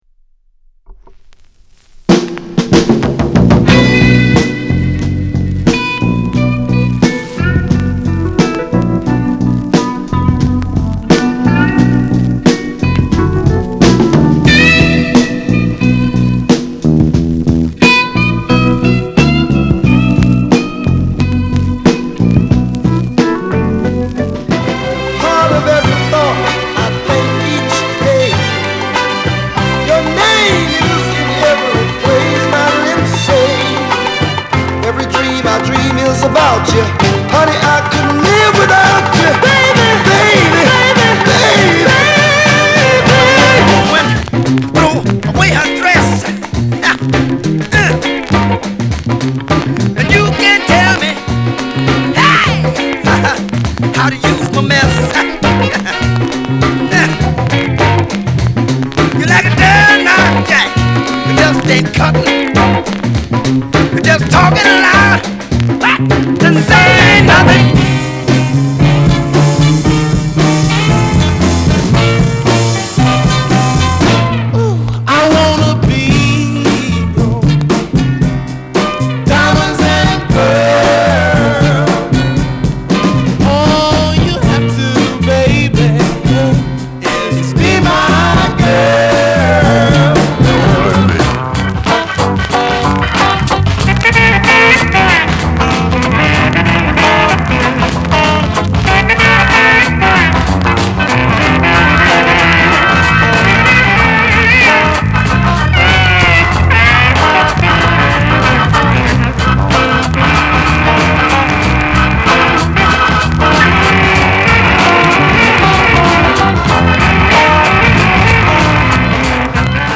ブルース